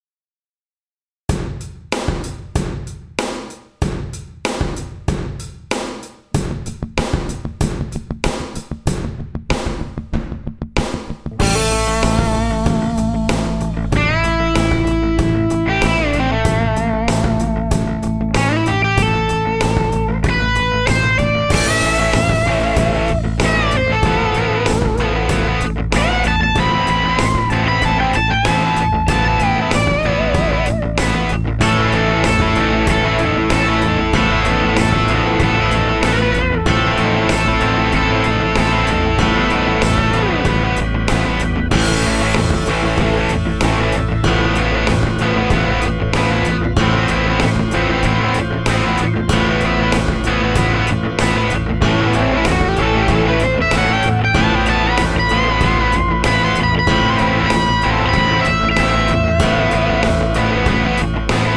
It's all '57 Stratocaster USA Reissue through the Tweed repro, no effects - other than a volume pedal and little Ambience reverb on the melody guitar. Not a great recording but some really nice funk tone: Panned right is the Strat rhythm, left is the Strat playing the bass line; hard right are Strat fills, center is the Strat playing melody. 4 Strat/Tweed tracks in all; Shure SM57 -> Redd.47 -> Pultec EQ (flat) -> La2a (compressing about -3dB).